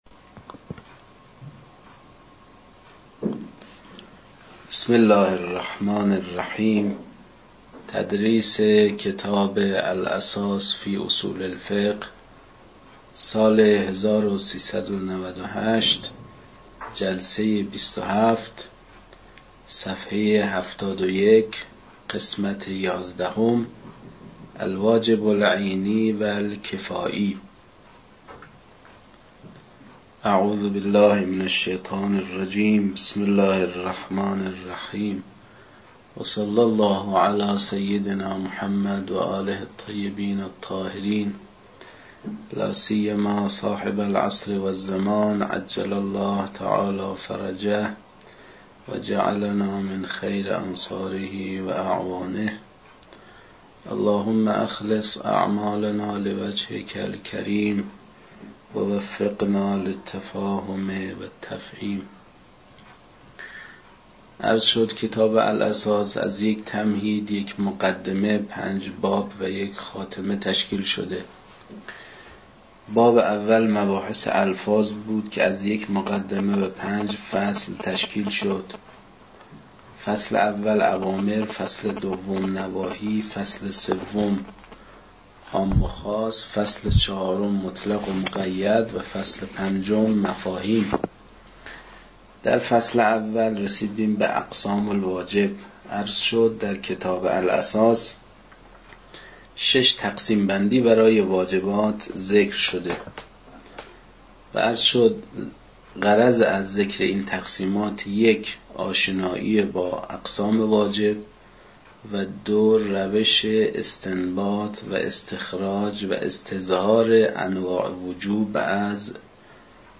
در این بخش، کتاب «الاساس» که اولین کتاب در مرحلۀ آشنایی با علم اصول فقه است، به صورت ترتیب مباحث کتاب، تدریس می‌شود.
در تدریس این کتاب- با توجه به سطح آشنایی کتاب- سعی شده است، مطالب به صورت روان و در حد آشنایی ارائه شود.